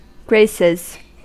Ääntäminen
Ääntäminen US : IPA : [ˈɡɹeɪs.ɪz] Haettu sana löytyi näillä lähdekielillä: englanti Käännöksiä ei löytynyt valitulle kohdekielelle. Graces on sanan grace monikko.